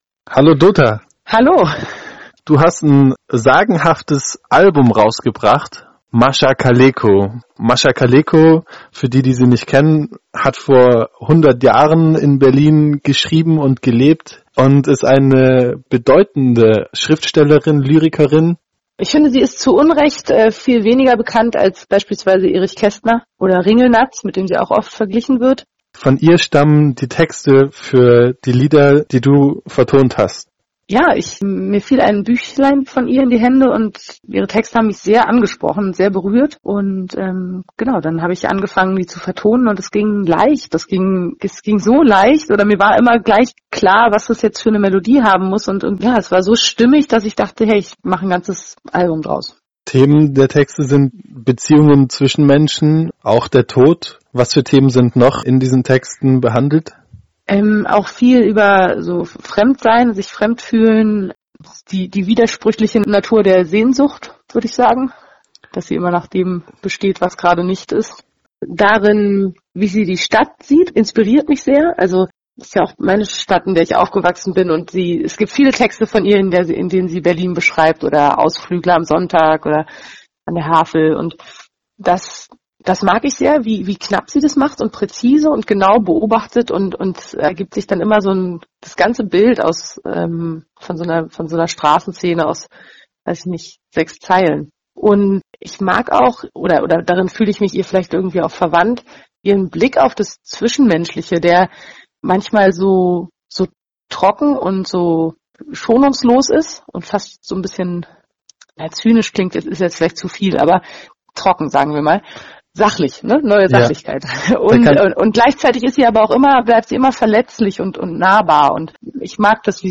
„Hallo Dota!“ – Ein Telefonat mit der Berlinerin